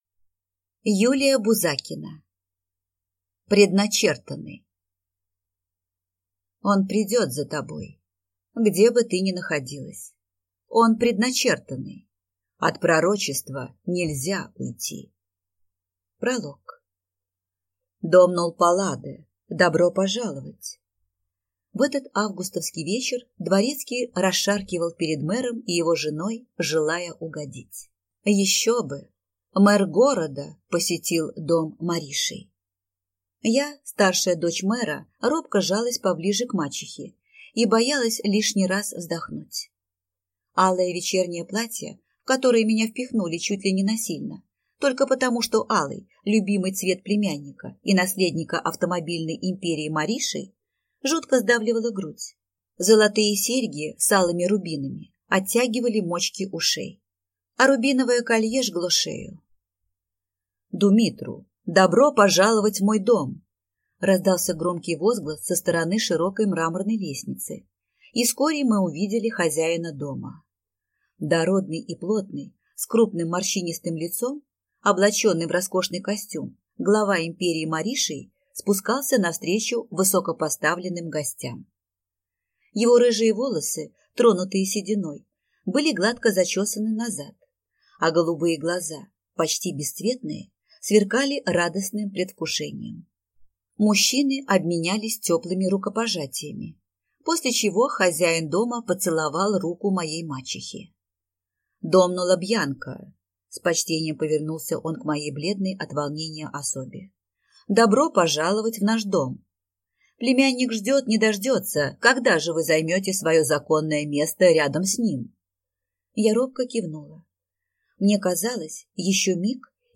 Аудиокнига Предначертанный | Библиотека аудиокниг